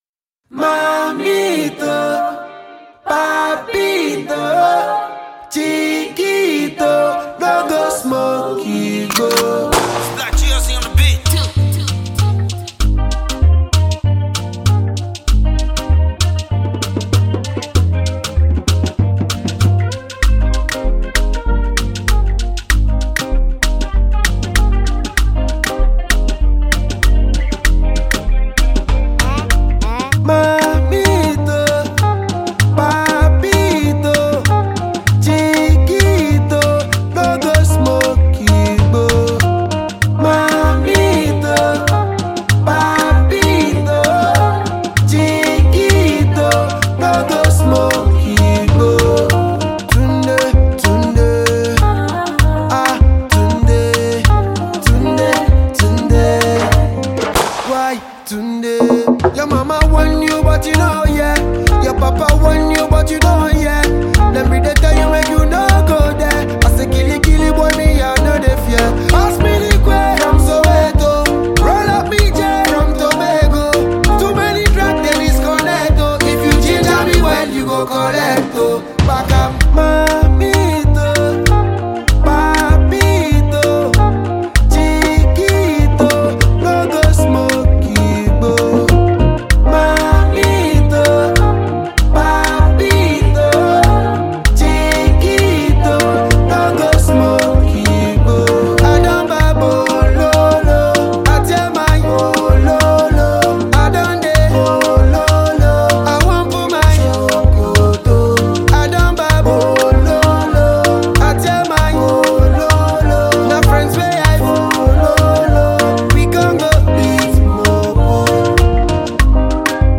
Nigerian dancehall singer and songwriter